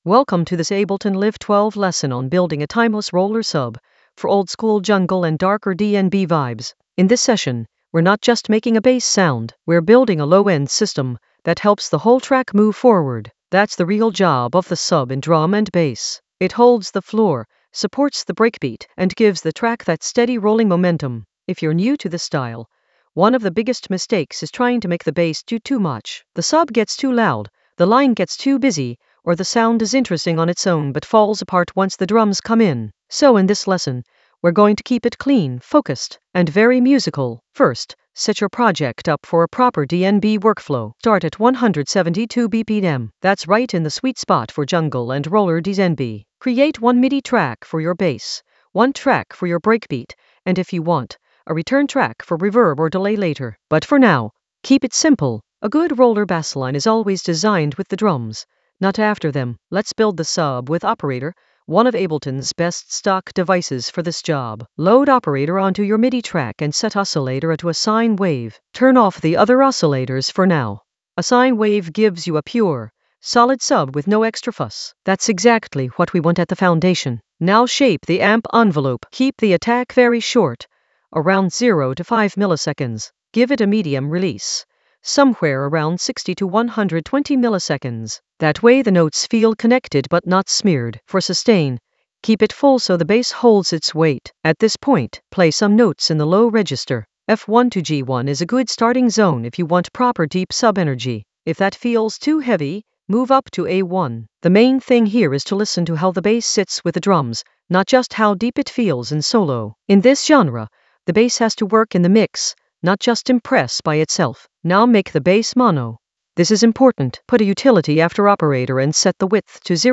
An AI-generated beginner Ableton lesson focused on Session for sub for timeless roller momentum in Ableton Live 12 for jungle oldskool DnB vibes in the Sound Design area of drum and bass production.
Narrated lesson audio
The voice track includes the tutorial plus extra teacher commentary.